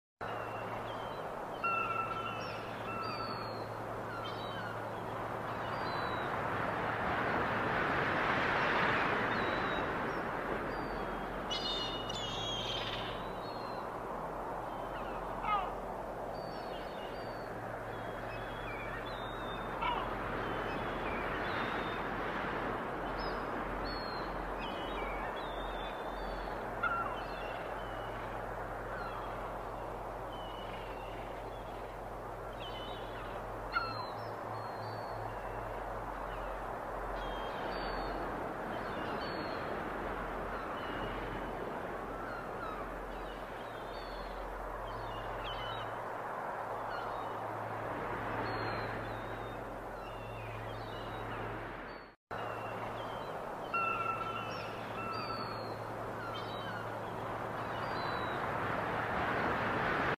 GTA 6 Ambient nature sounds sound effects free download